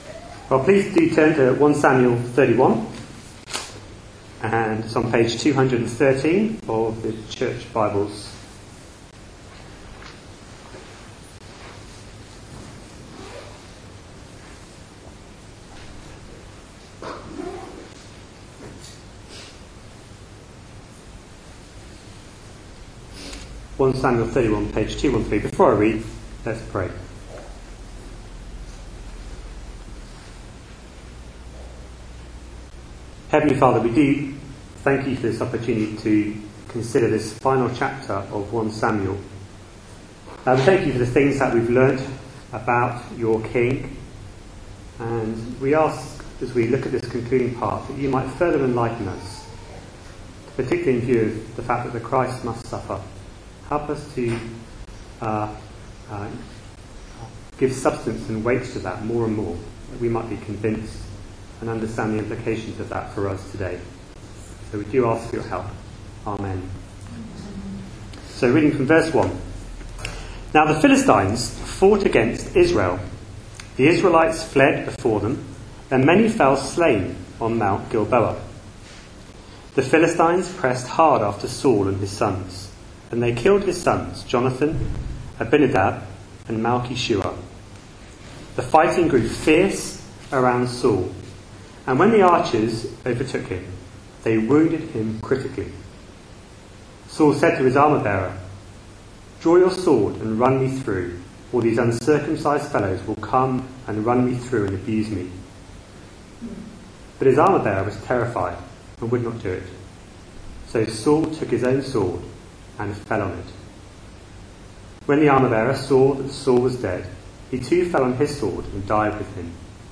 A sermon preached on 25th August, 2013, as part of our God's King? series.